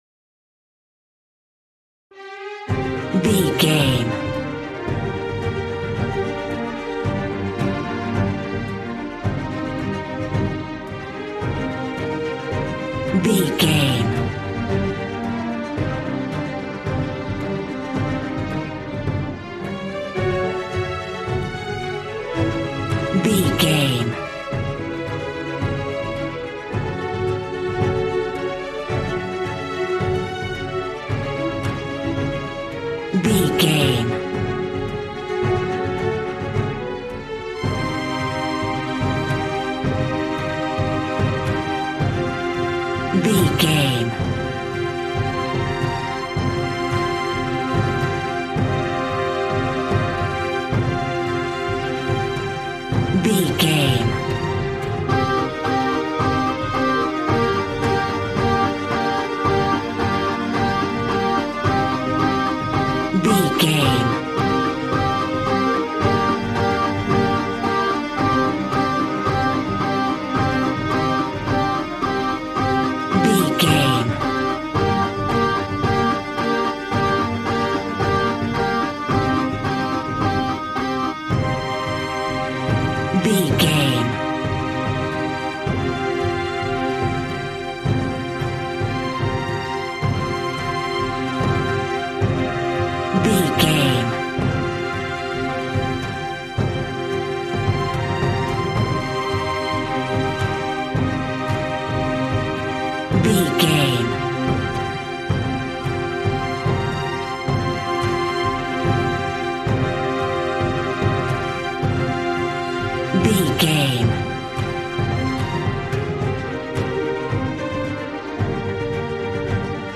A dark and scary piece of tense classical music.
Aeolian/Minor
suspense
piano
synthesiser